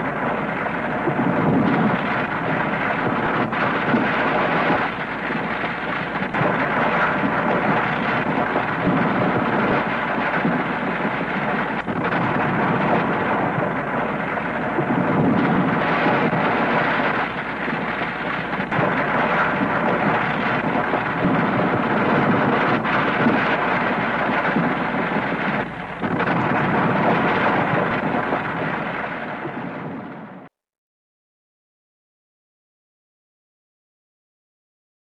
地震効果音約 30秒（WAV形式 約6MB）
地震効果音はフリー音源を上記の秒数に編集したものです。